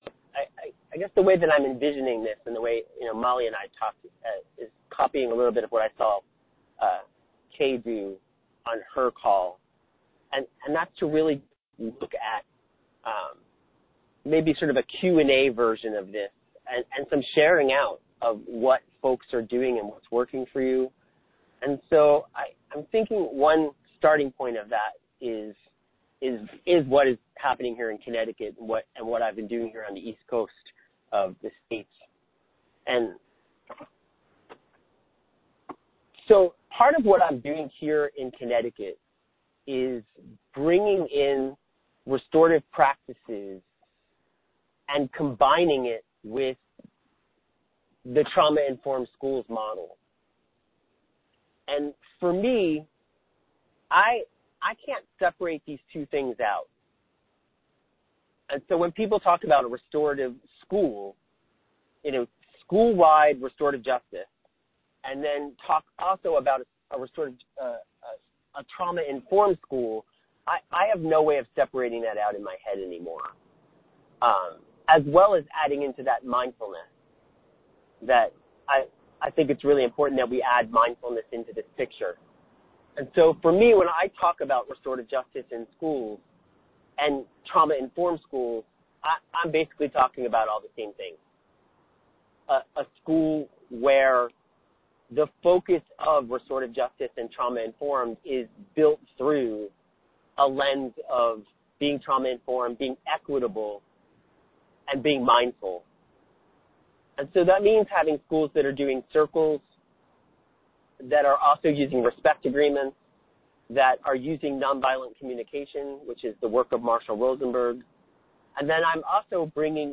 Mentoring Session